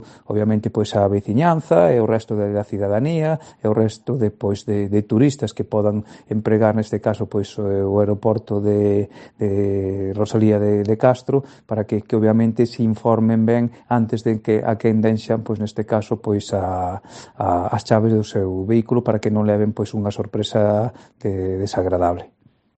AUDIO: Declaraciones